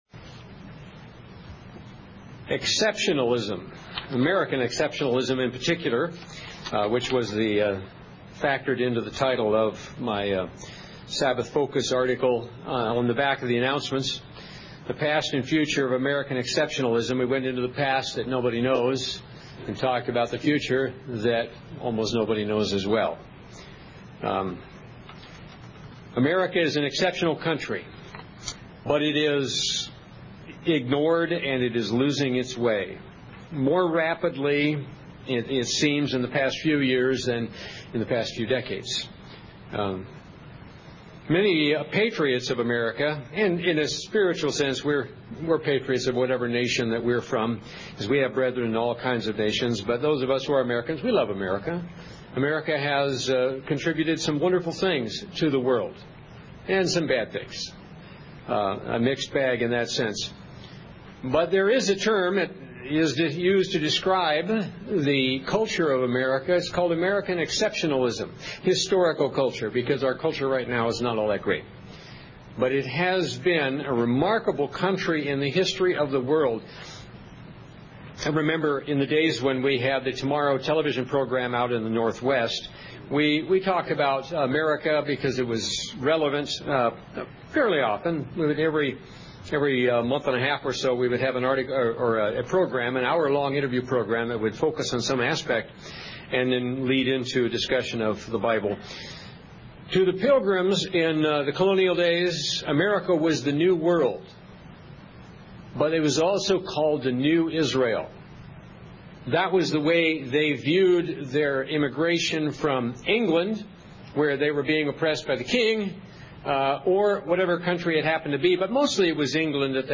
Given in North Canton, OH
UCG Sermon Studying the bible?